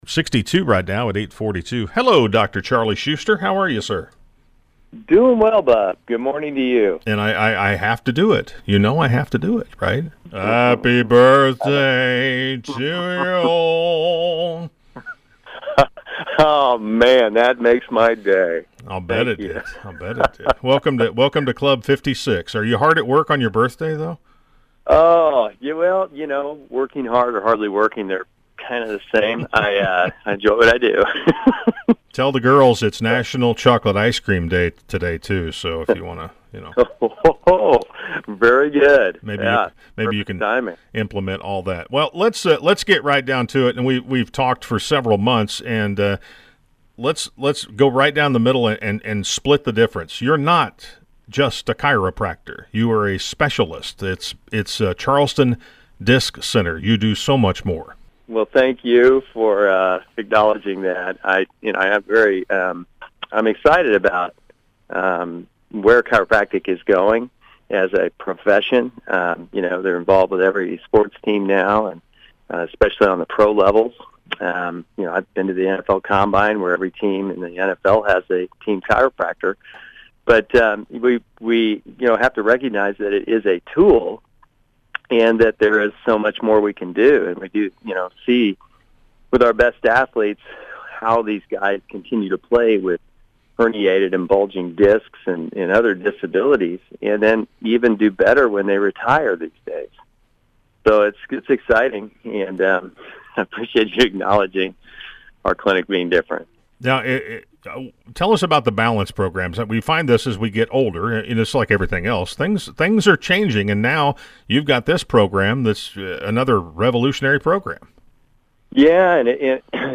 by Radio Interview